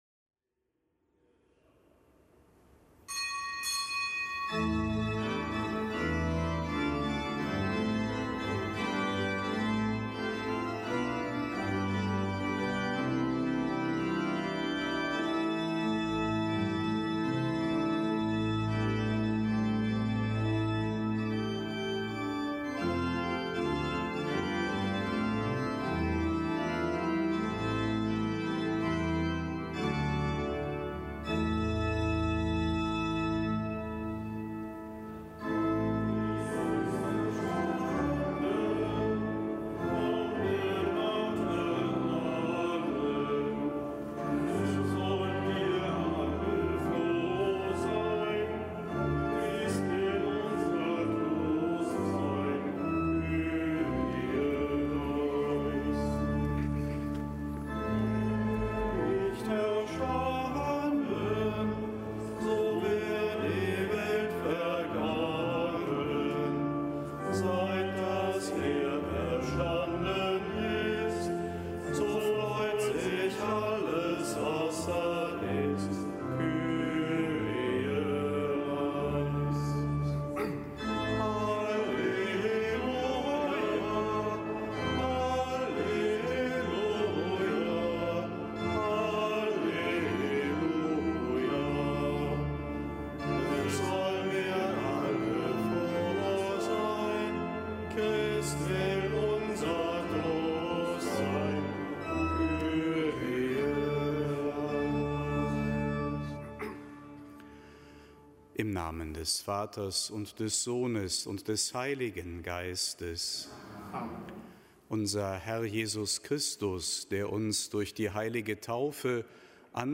Kapitelsmesse am Donnerstag der vierten Osterwoche
Kapitelsmesse aus dem Kölner Dom am Donnerstag der vierten Osterwoche